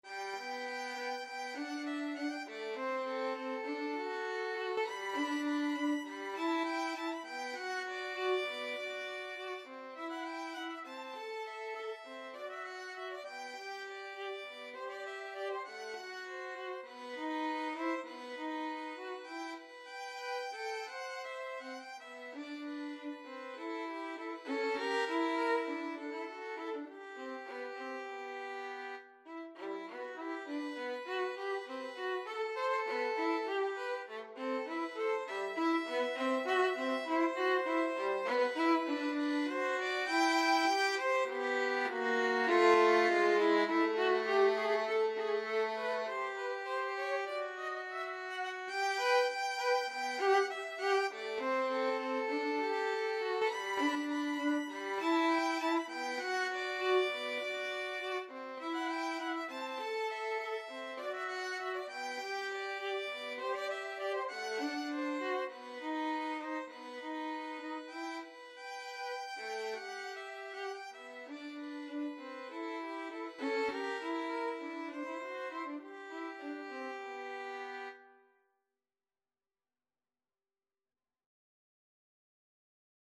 Allegretto
Violin Duet  (View more Intermediate Violin Duet Music)
Classical (View more Classical Violin Duet Music)